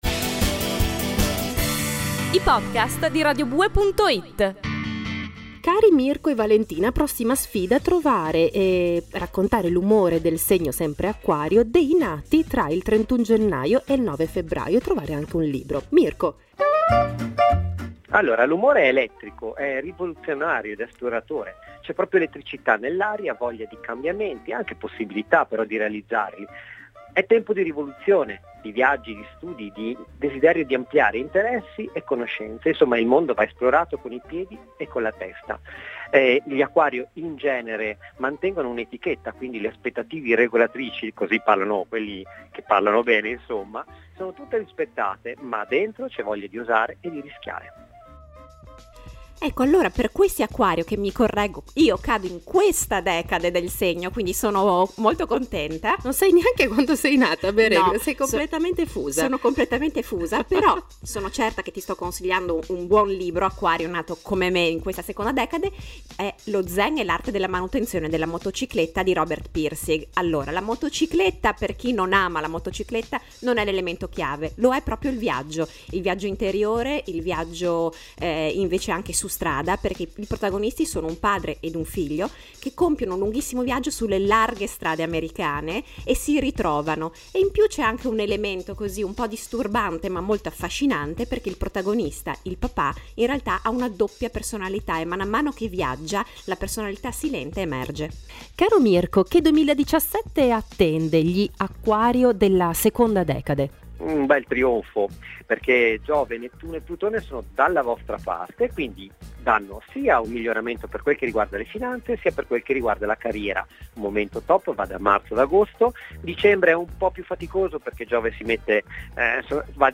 Libri e Oroscopo: riprendiamo anche nel 2017 lo spazio dedicato ai romanzi consigliati in base al proprio segno zodiacale con una (bella) novità: una nuova voce “radiofonica e astrologica”.